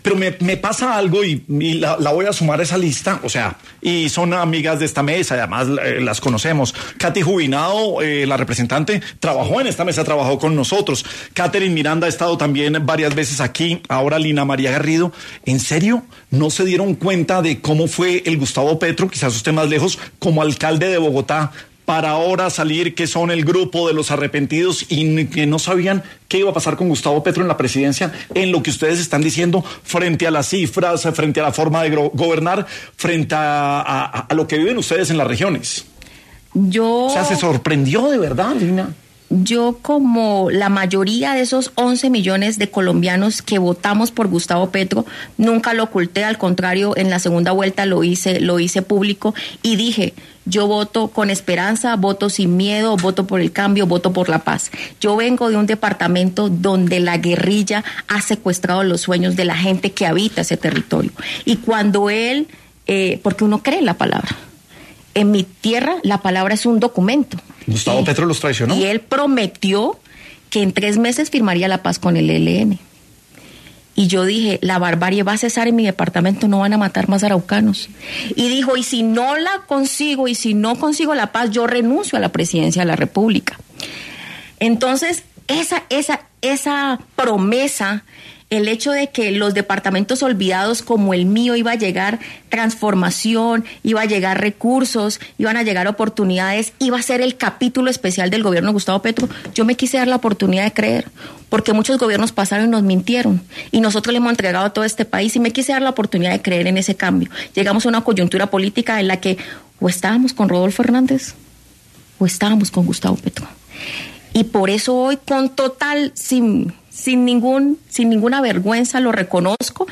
En este contexto, Garrido pasó por los micrófonos de La Luciérnaga, en donde abordó las promesas incumplidas de Petro, en relación con el ELN.